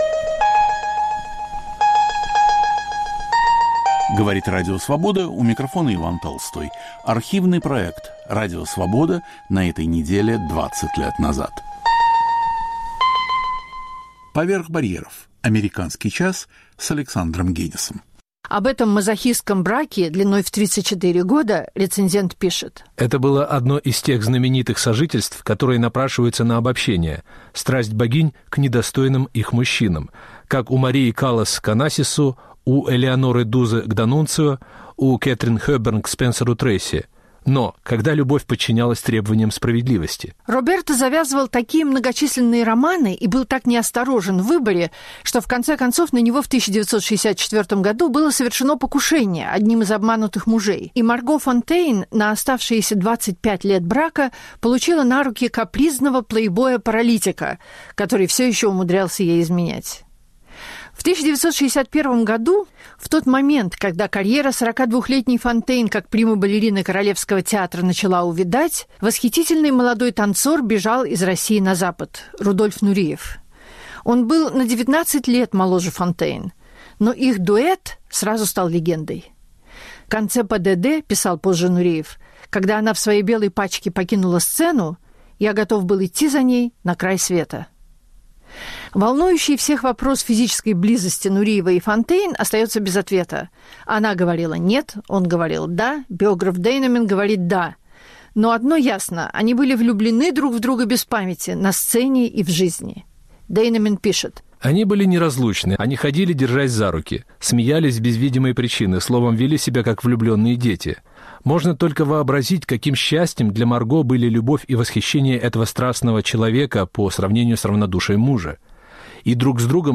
Редактор и ведущий Александр Генис.